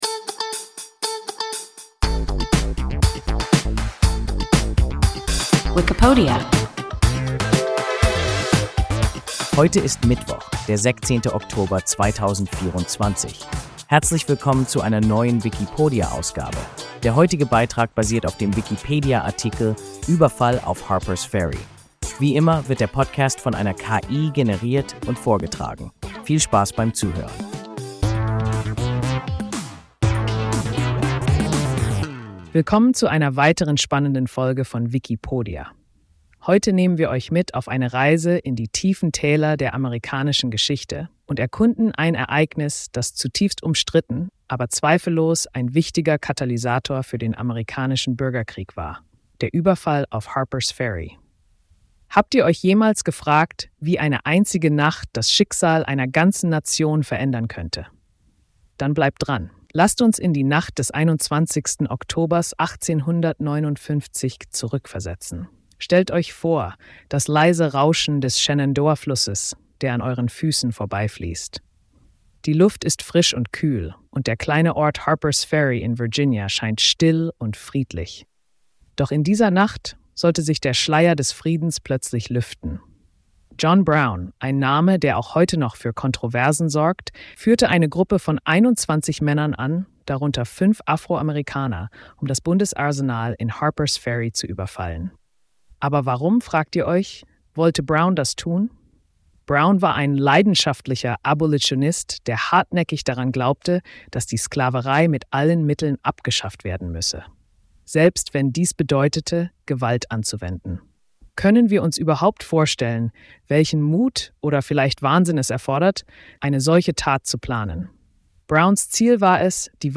Überfall auf Harpers Ferry – WIKIPODIA – ein KI Podcast